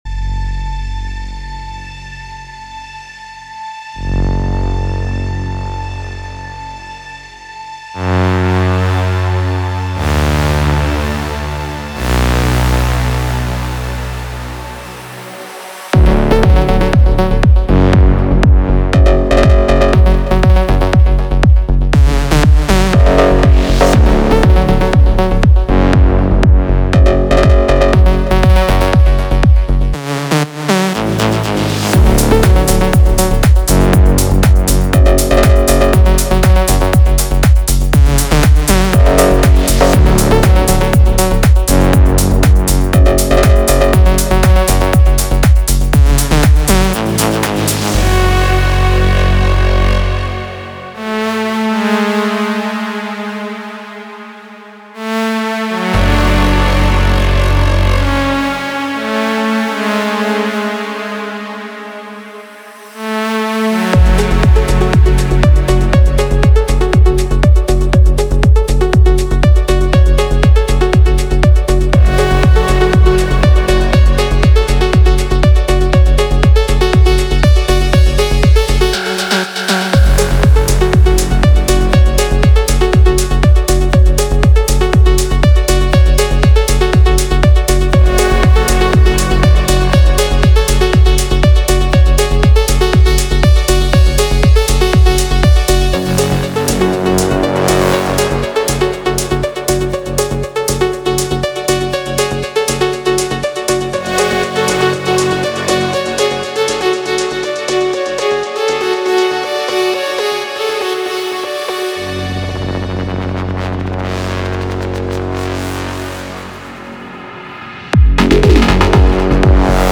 techno presets for the u-he diva synthesizer
With its deep character and innovative sound design, Diva Melodic Techno features 50 Diva Presets that include a wide variety of synth leads, rich analogue-style bass, arp presets, drifting pads, fx, and distinctive plucks.
This deep, warm tone is one you don’t want to miss.
In detail, the sound bank contains: 4 Arp, 12 Bass, 5 FX, 11 Lead, 10 Pad and 8 Pluck presets.
RS Melodic Techno Diva Presets Demo.mp3